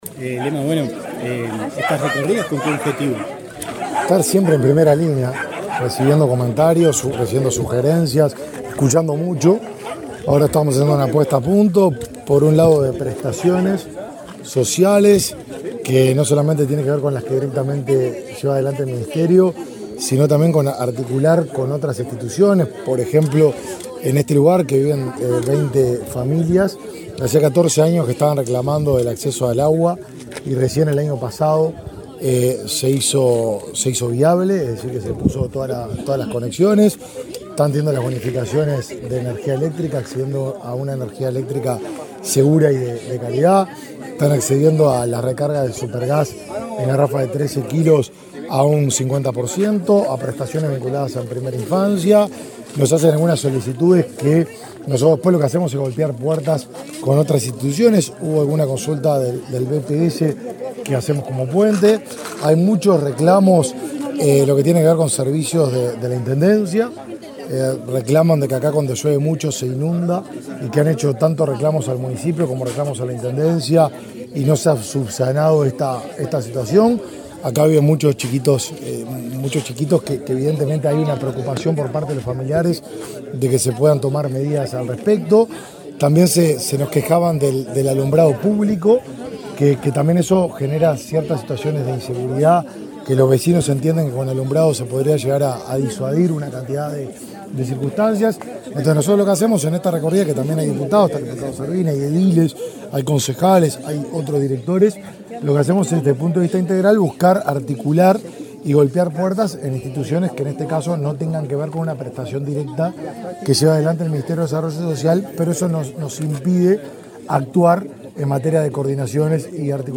Declaraciones del ministro de Desarrollo Social, Martín Lema
El ministro de Desarrollo Social, Martín Lema, dialogó con la prensa durante una recorrida por Canelones, donde visitó varios asentamientos